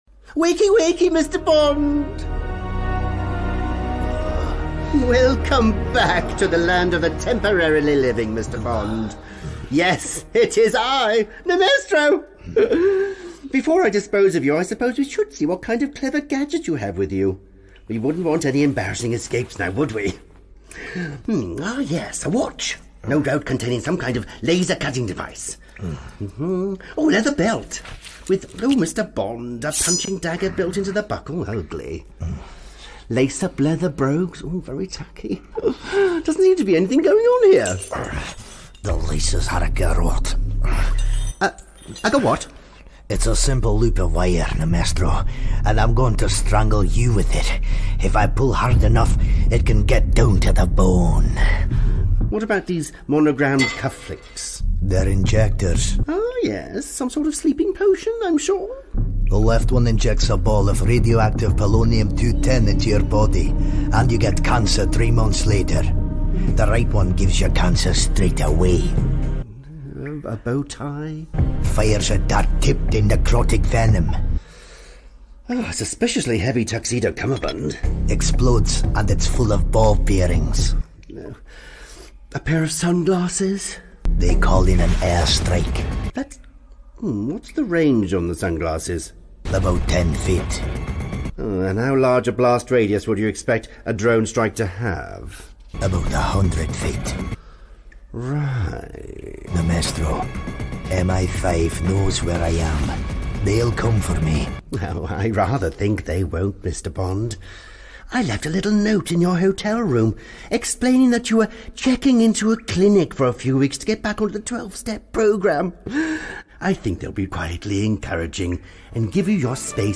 It's a fun little parody sketch I wrote and recorded years ago in 2016, but haven't fully sound engineered until now.